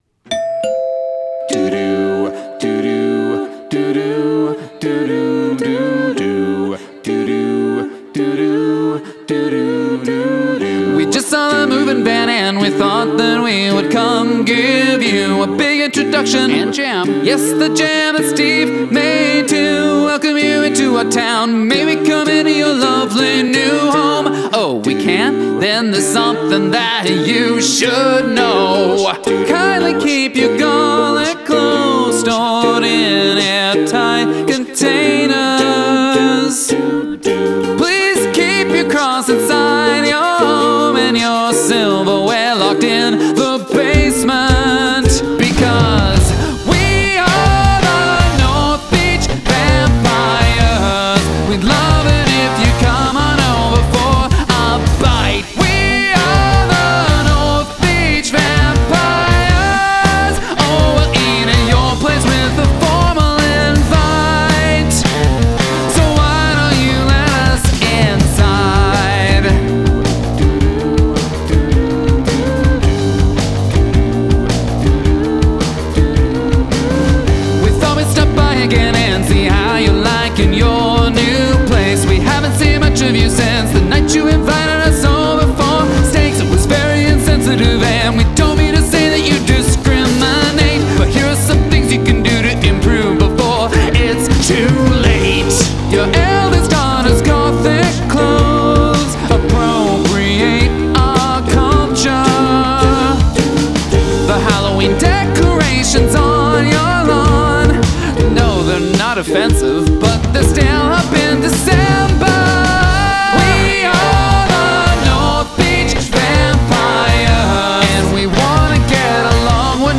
Include an a cappella section